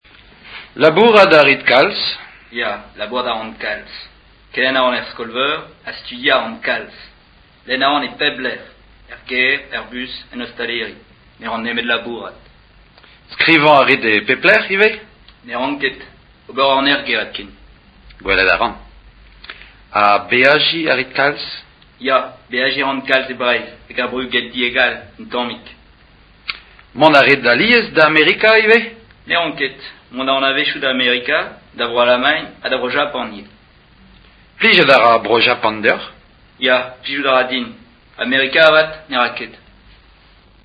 breton2m.mp3